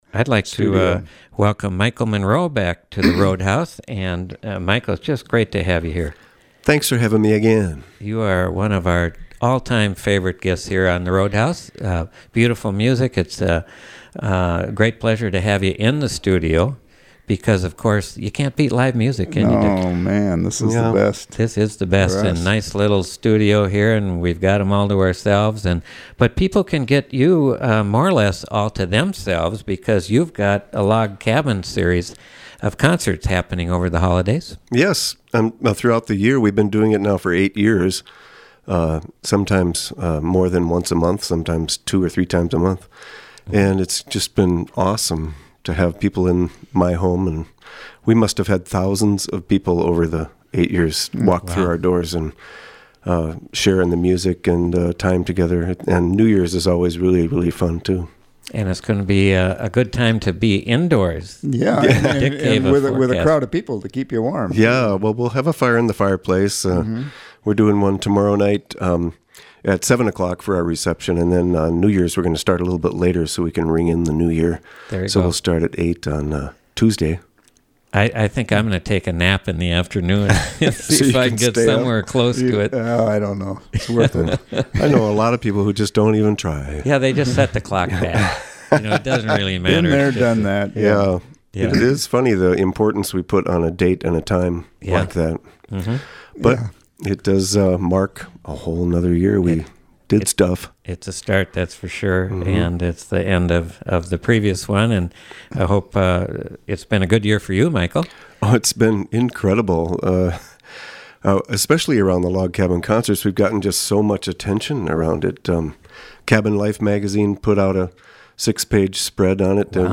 music and conversation